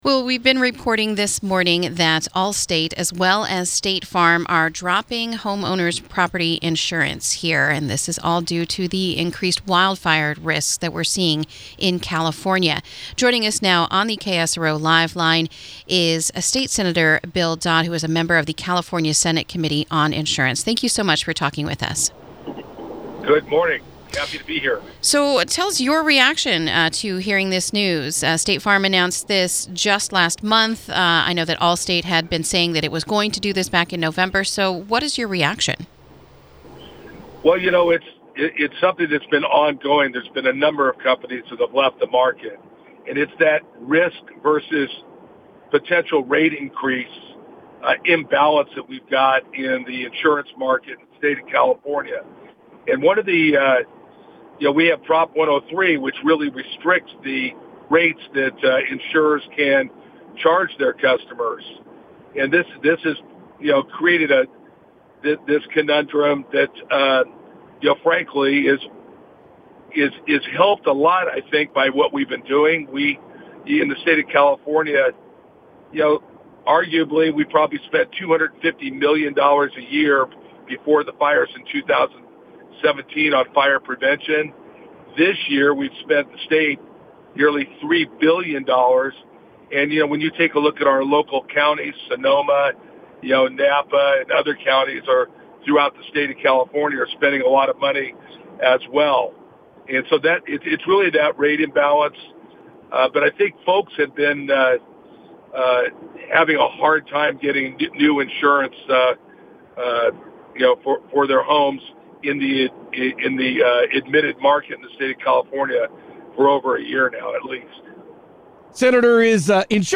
spoke with State Senator Bill Dodd, member of the California Senate Committee on Insurance, regarding this move by Allstate.